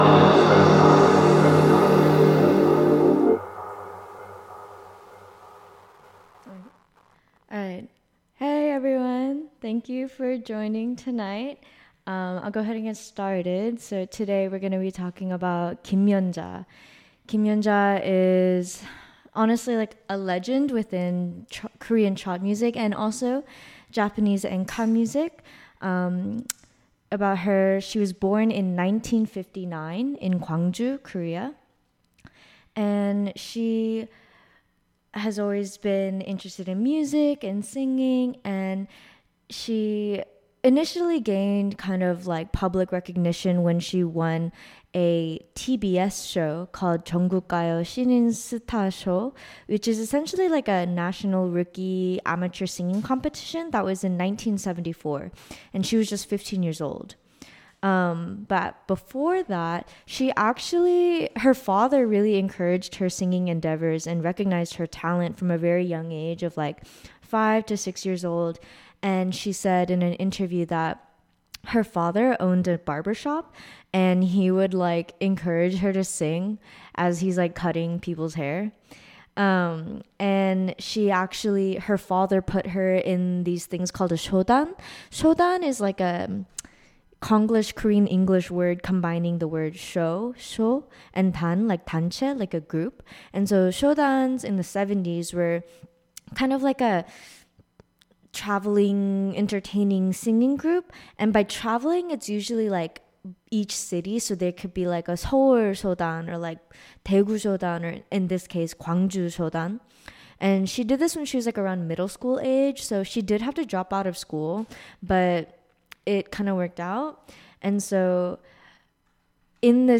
Korean Traditional